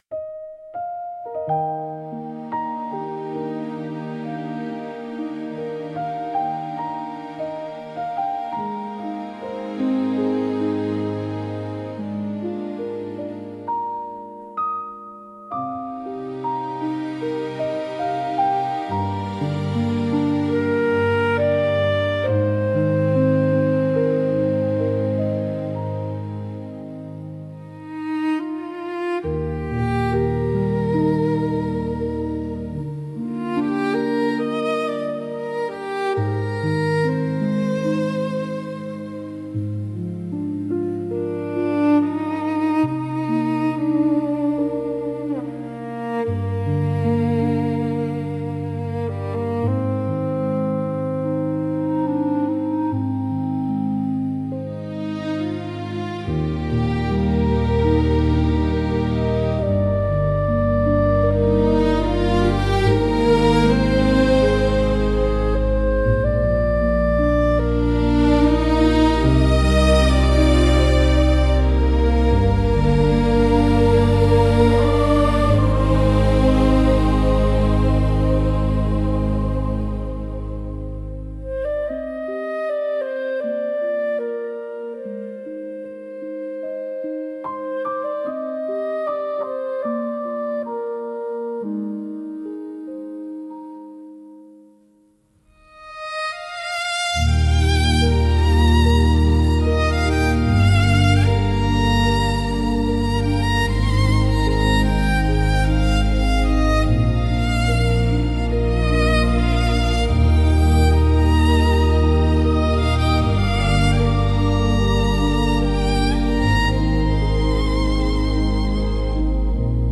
In the Arms of Love - Instrumental